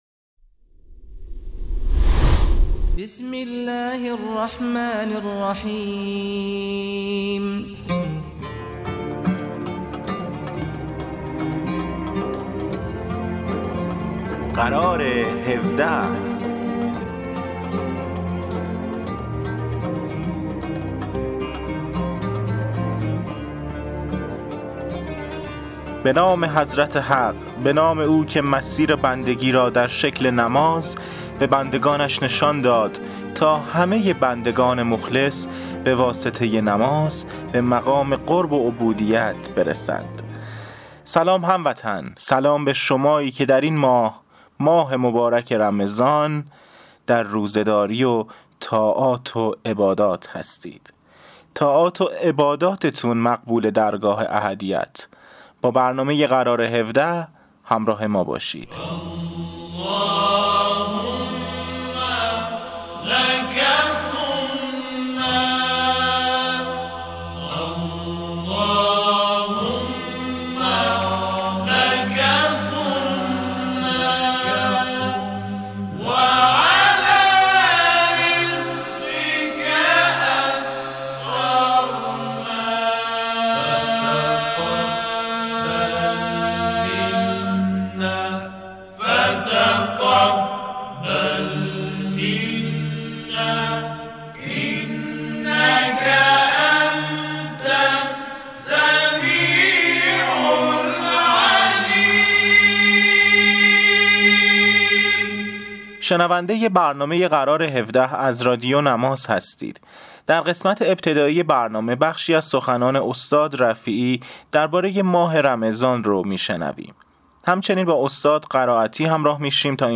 برنامه اینترنتی قرار هفده مجموعه ای از آیتم های نمازی و در مورد بخش های مختلف از نماز، دارای تواشیح، سخنرانی های نمازی، سرود و ترانه، دلنوشته، خاطرات و معرفی کتاب و … است.